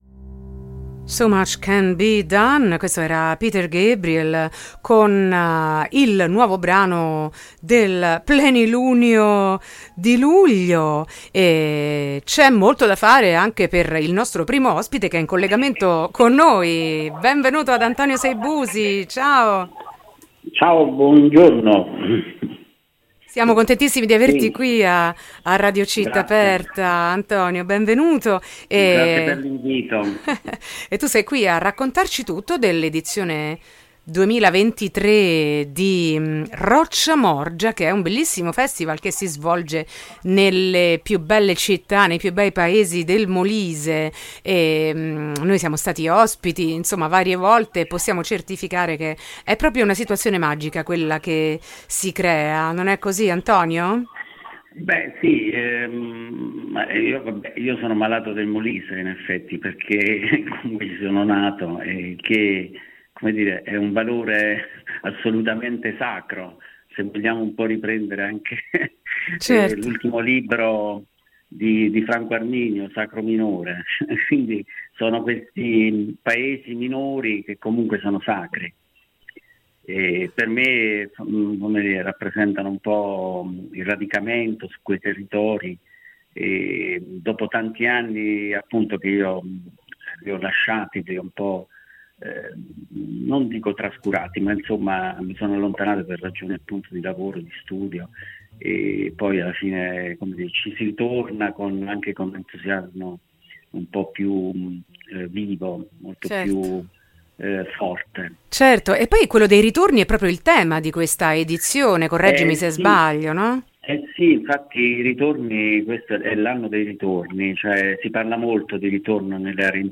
Riscoprire il Molise con il Festival Rocciamorgia: intervista